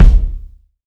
Kicks
KICK.122.NEPT.wav